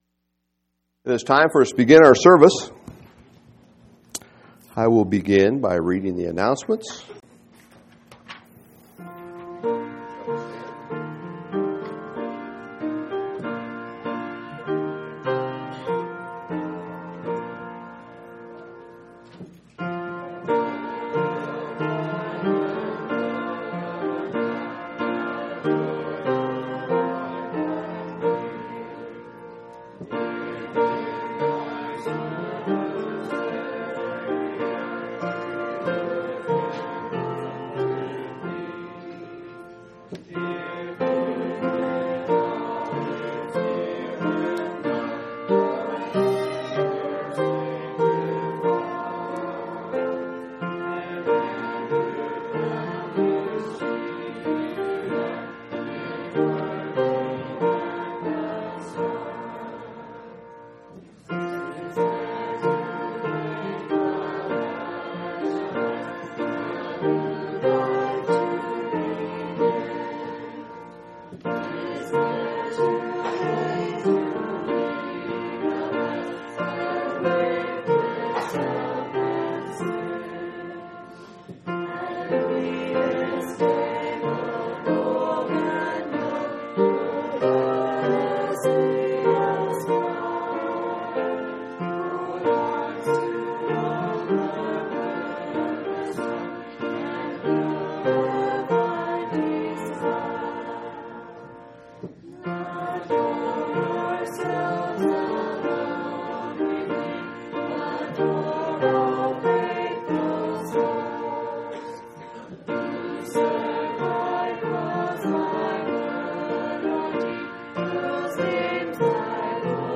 7/11/2004 Location: Phoenix Local Event